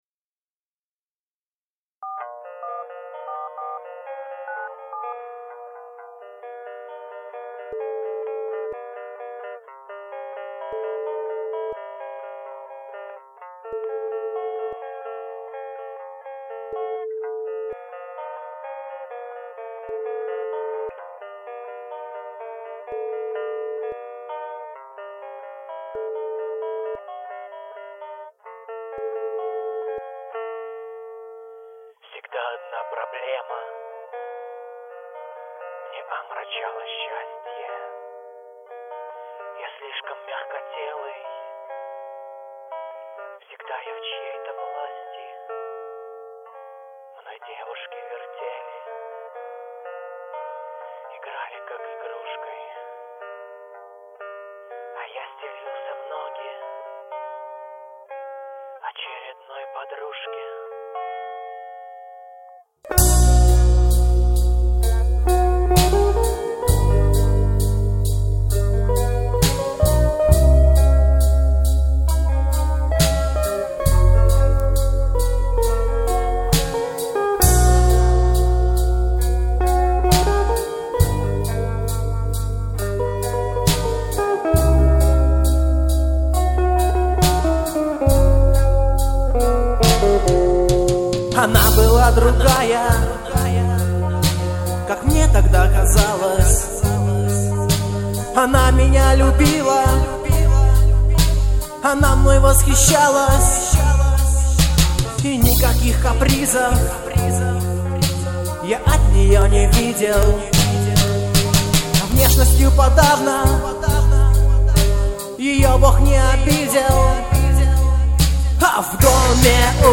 Гитары, бас, вокал
Барабаны